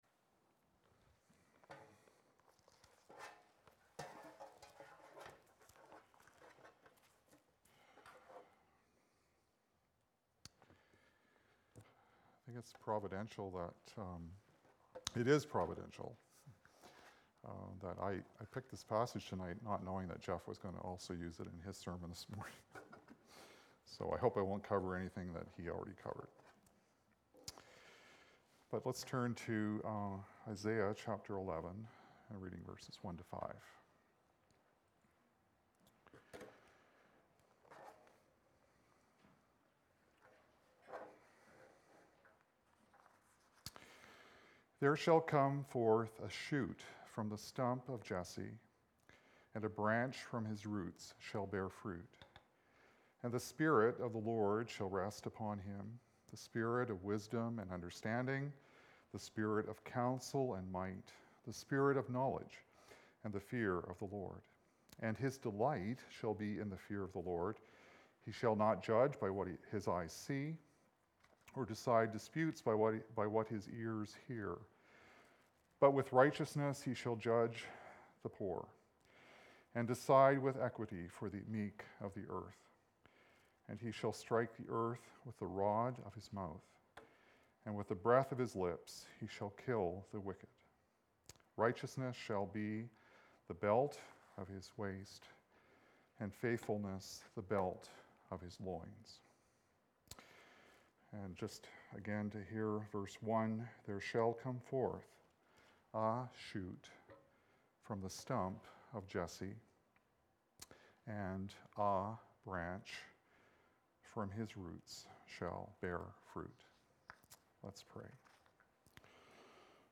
Series: Evening Service Sermons 2018